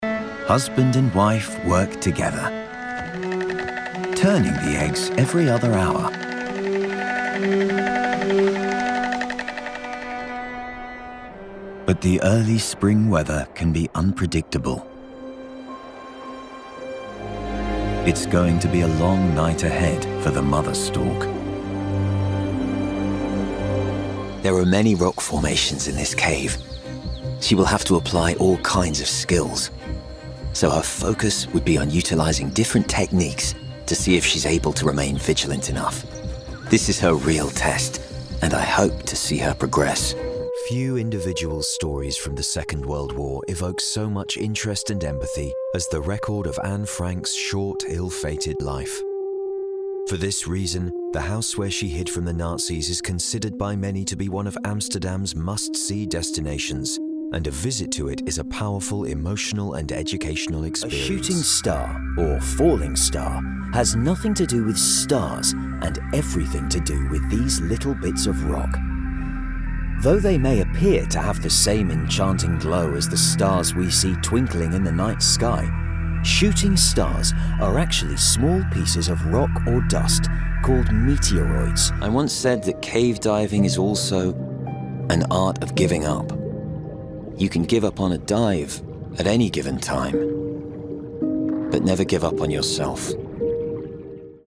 Male
Assured, Authoritative, Confident, Corporate, Deep, Engaging, Gravitas, Posh, Reassuring, Smooth, Warm, Witty
Microphone: Sontronics Aria Cardioid Condenser Valve Mic, Shure SM7B